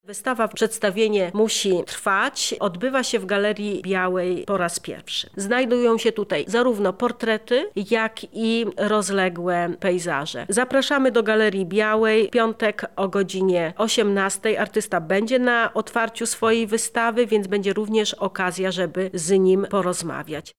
O wydarzeniu rozmawialiśmy